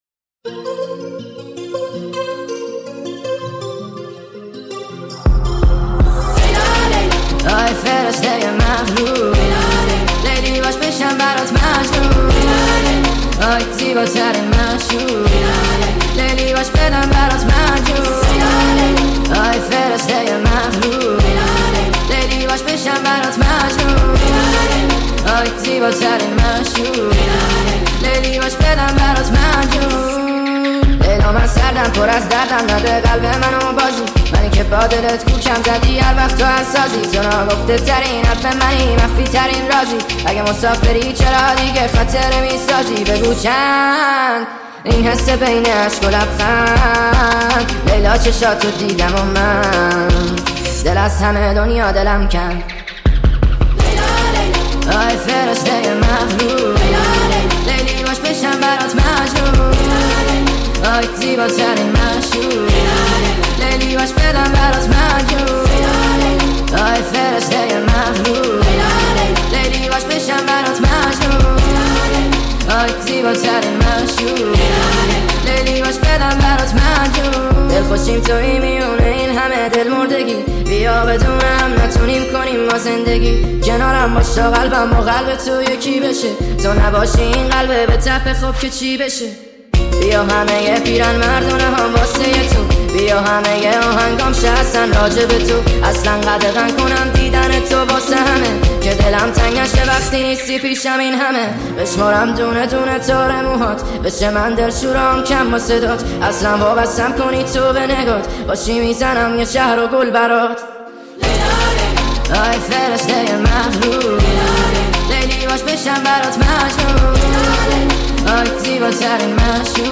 ریمیکس جدید
Remix